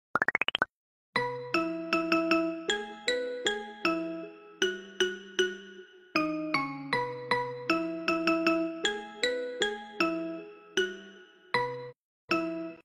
🎶✨ Try your hands on sound effects free download By rvappstudios 5 Downloads 18 hours ago 12 seconds rvappstudios Sound Effects About 🎶✨ Try your hands on Mp3 Sound Effect 🎶✨ Try your hands on the xylophone! Watch, tap, and enjoy the playful tunes 🥳 Can you guess which song the xylophone is playing?